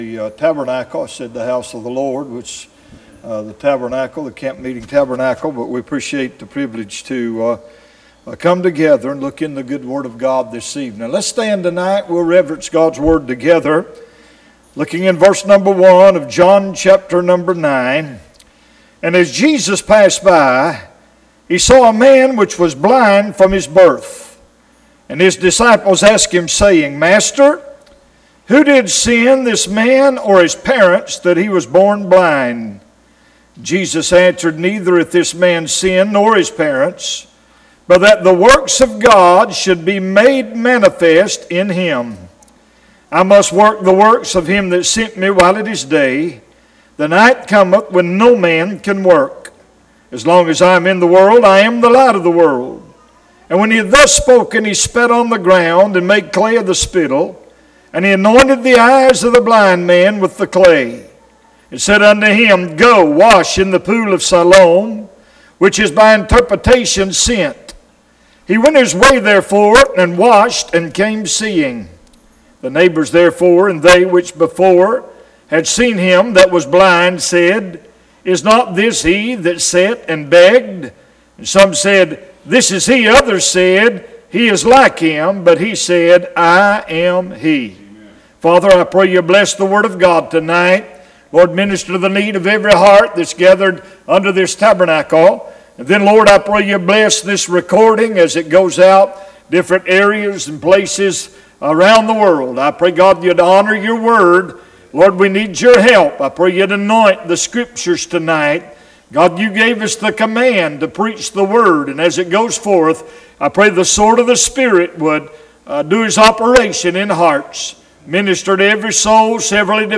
Series: 2025 July Conference
Session: Evening Session